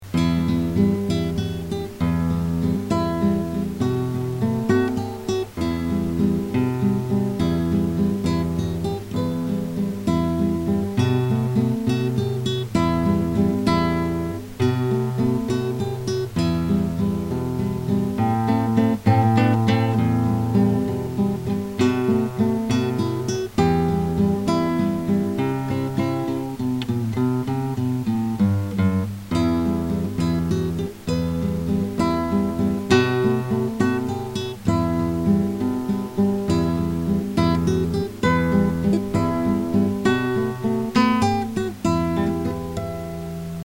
I've been studying classical guitar since 2005, creating interactive music tools and adapting Renaissance-era compositions for modern practice.
Practice (songs)